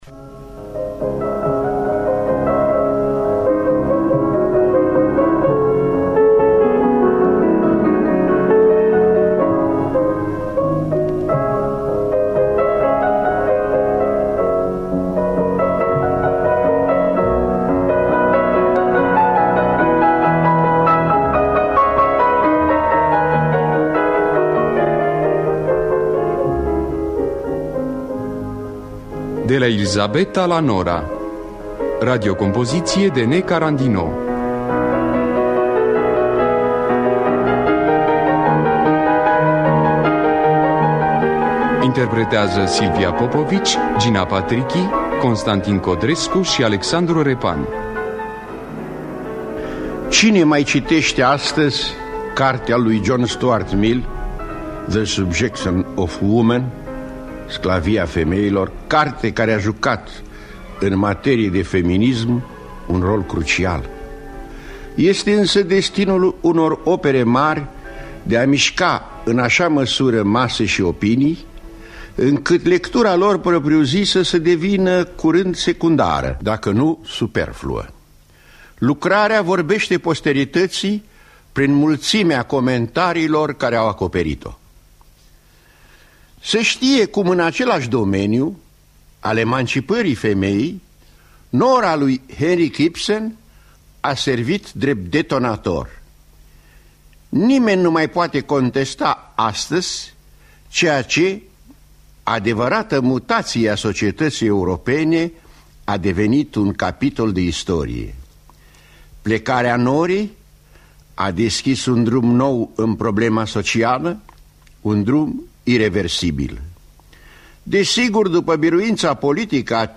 De la Elisabeta la Nora de Nicolae Haralambie Carandino – Teatru Radiofonic Online
Înregistrare din anul 1973.